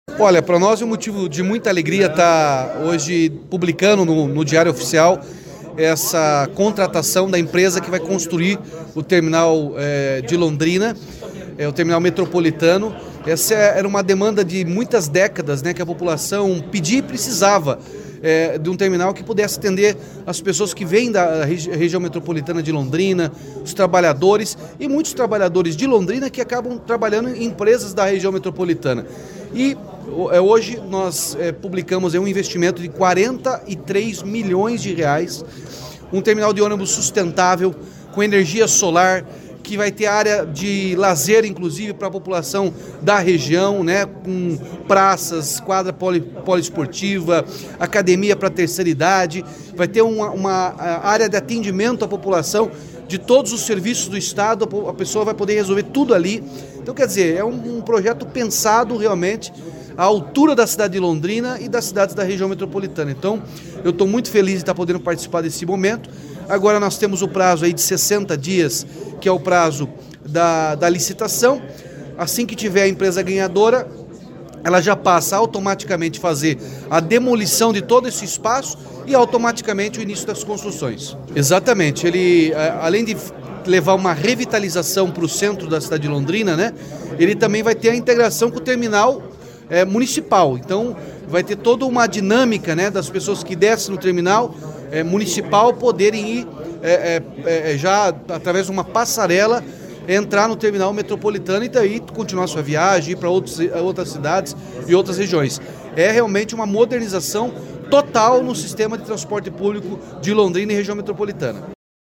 Sonora do governador Ratinho Junior sobre o edital para construção do Terminal Metropolitano de Londrina